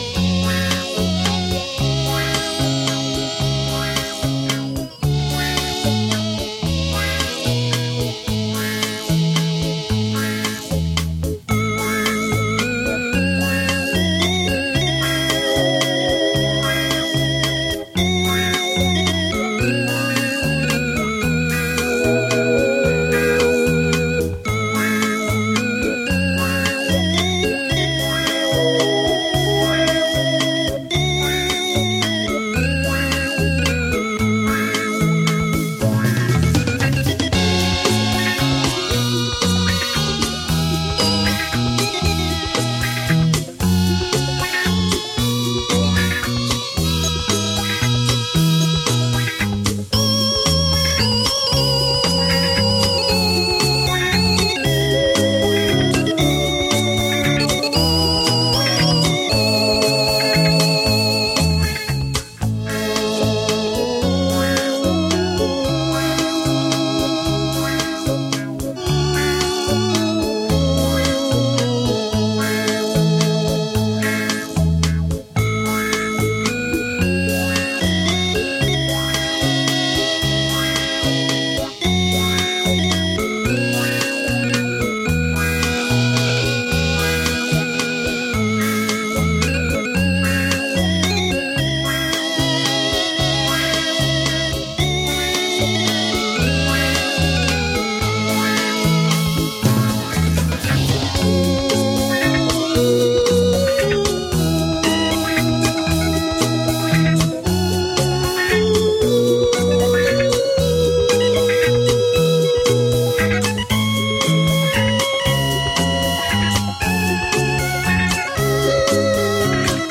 完美电声的怀旧之情，演译逶婉动情馨声曲。